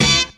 taunt.wav